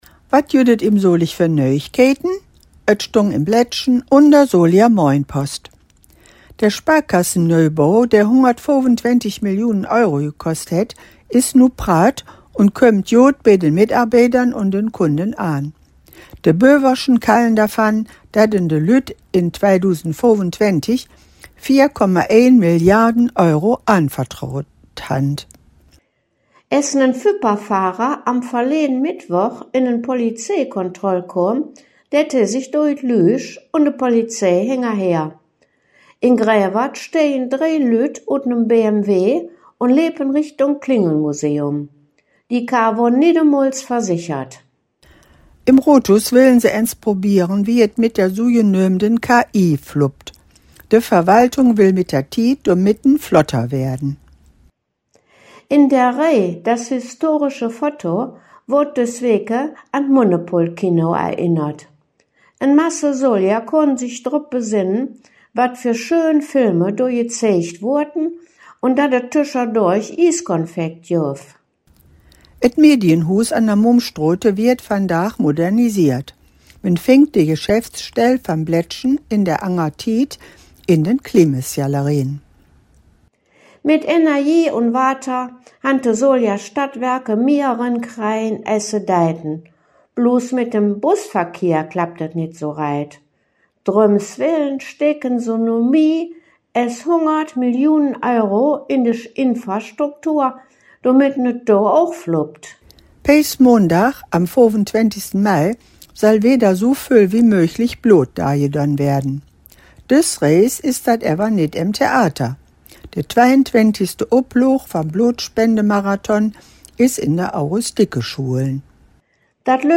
Solinger Platt
Der in Solingen gesprochene Dialekt wird Solinger Platt genannt.
Zugleich wird im Solinger Platt aber auch die Nähe zum ripuarischen Sprachraum (vor allem durch das Kölsch bekannt) hörbar.